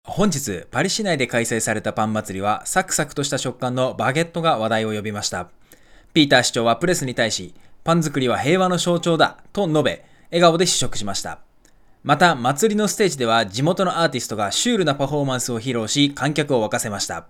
またH6 Essentialについてはステレオ収録のため､今回は編集でモノラルにして試聴してもらう。
音声収録は全てH6 Essentialに同時録音をしたので読み上げの誤差は全く無い状態だ。
H6 EssentialのステレオマイクとワイヤレスピンマイクのURX-P03d UTX-B40の組合せの場合は部屋の反響音を拾っている感じがあり､マイクの特性が異なる感じはあった。（とはいえ音質が悪いとは感じ無かった）
【マイク2(H6 Essential)】
fifine-k688-review-Audio-test-h6e.mp3